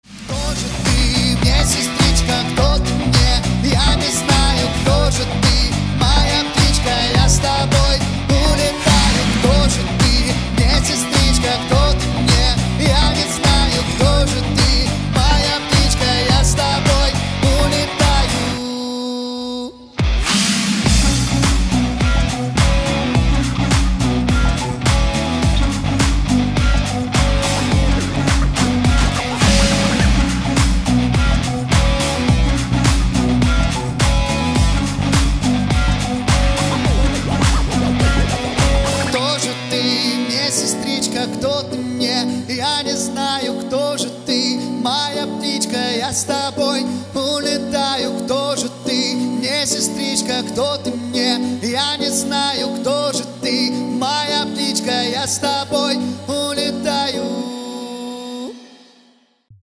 • Качество: 256, Stereo
поп
мужской вокал
dance